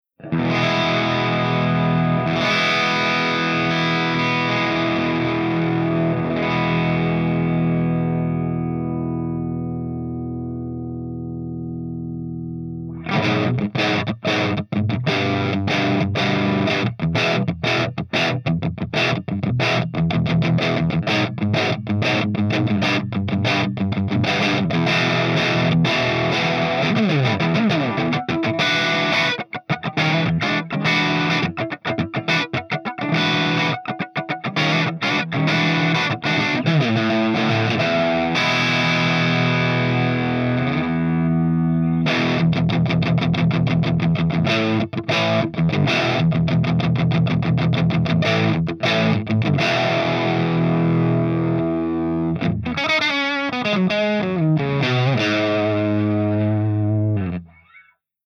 111_PLEXI_CH1+2HIGHDRIVE_GB_SC
111_PLEXI_CH12HIGHDRIVE_GB_SC.mp3